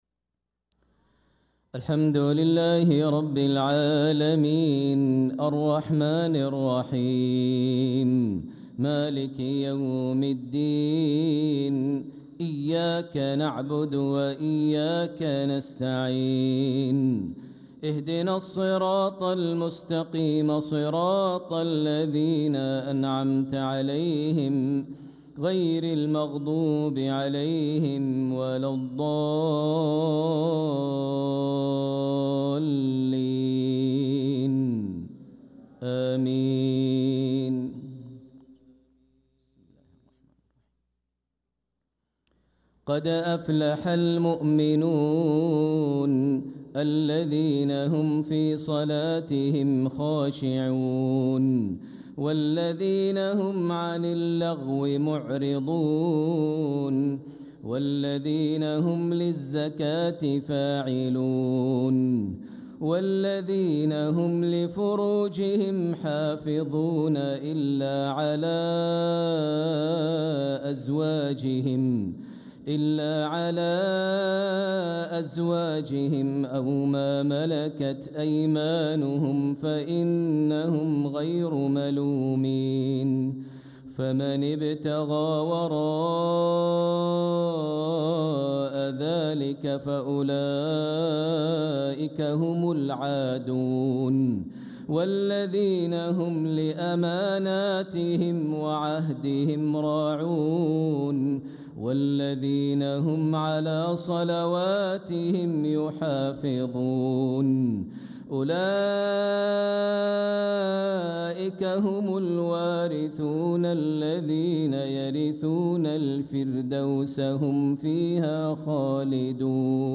صلاة العشاء للقارئ ماهر المعيقلي 24 جمادي الأول 1446 هـ
تِلَاوَات الْحَرَمَيْن .